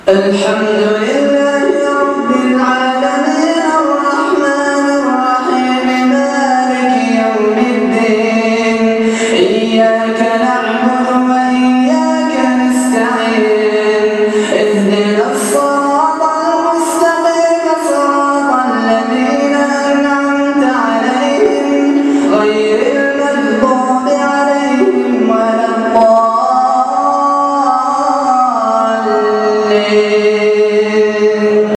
تلاوت رائعة